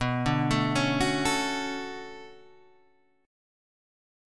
B Minor 9th